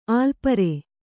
♪ ālpare